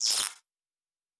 pgs/Assets/Audio/Sci-Fi Sounds/Electric/Spark 02.wav at master
Spark 02.wav